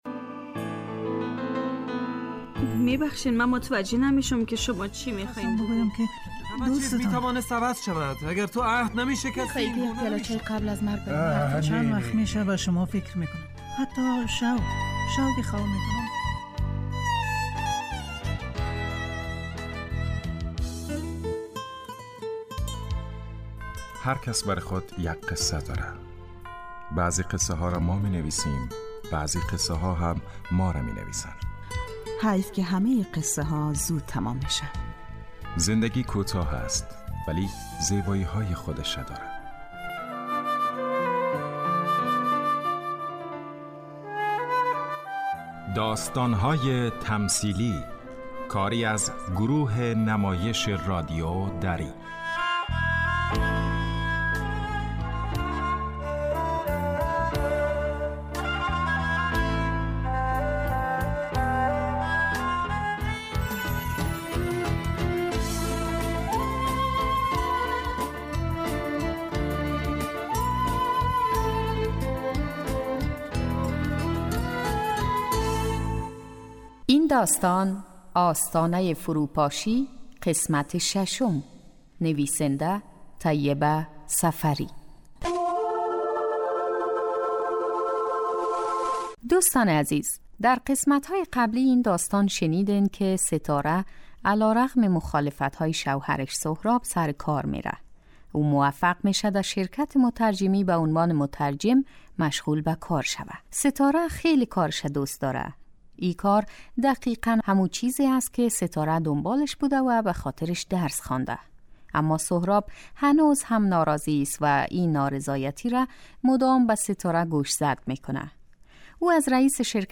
داستانهای تمثیلی نمایش 15 دقیقه ای هستند که هر روز ساعت 3:30 عصربه وقت وافغانستان پخش می شود.